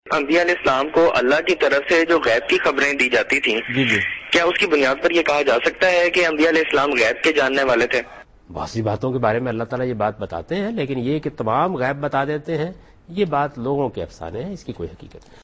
Category: TV Programs / Dunya News / Deen-o-Daanish /
Javed Ahmad Ghamidi answers a question regarding "Knowledge of the Unseen" in program Deen o Daanish on Dunya News.